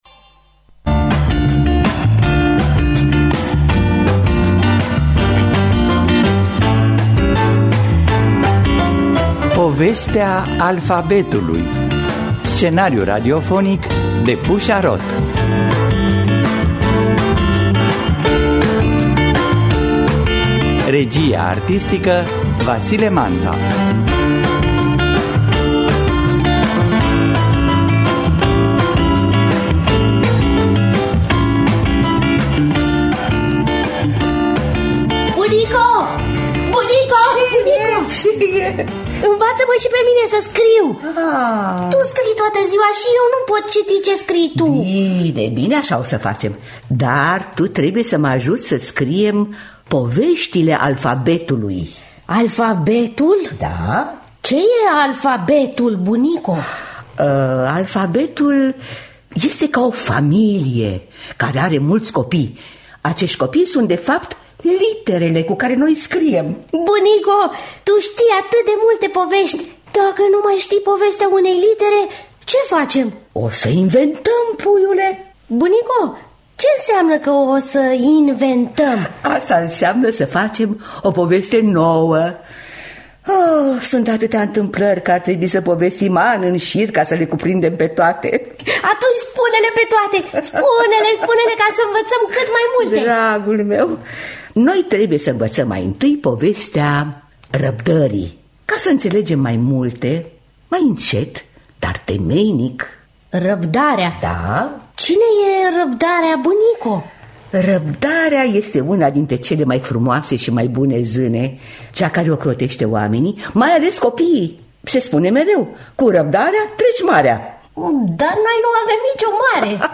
“Povestea alfabetului”. Scenariu radiofonic